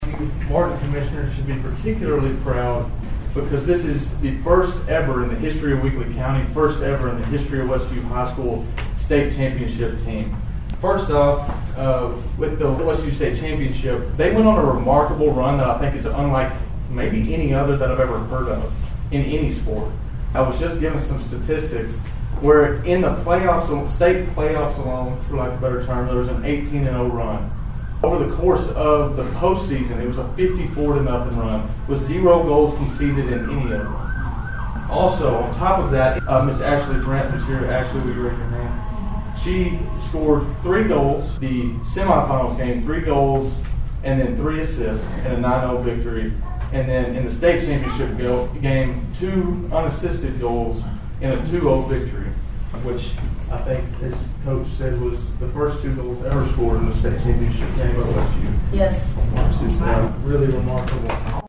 It was a full house at Monday’s Weakley County Commission meeting.